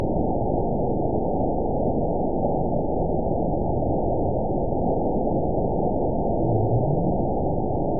event 917615 date 04/10/23 time 06:26:23 GMT (2 years ago) score 9.50 location TSS-AB01 detected by nrw target species NRW annotations +NRW Spectrogram: Frequency (kHz) vs. Time (s) audio not available .wav